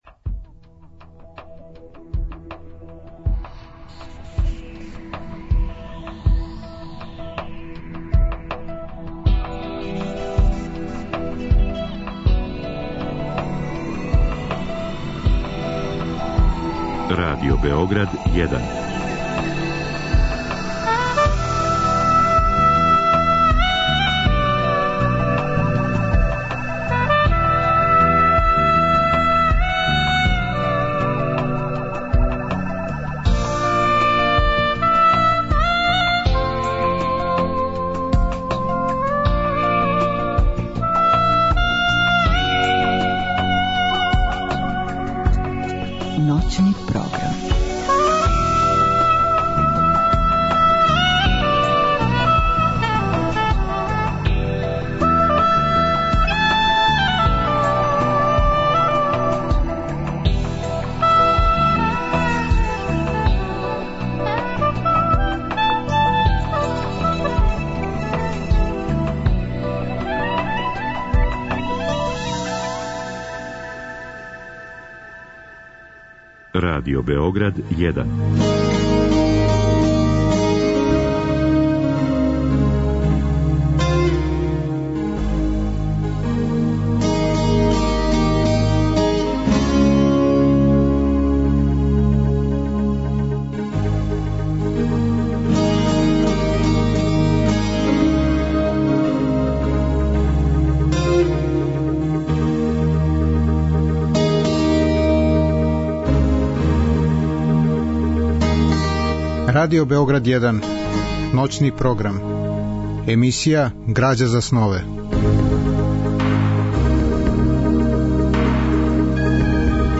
Разговор и добра музика требало би да кроз ову емисију и сами постану грађа за снове.
Слушаћемо тако одабране делове из радио-драматизације лирског романа Милоша Црњанског Дневник о Чарнојевићу, а потом делове радио-драме Игуманова сен, рађенe по причи Драгутина Илића, писца који се сматра једним од родоначелника српске научне фантастике.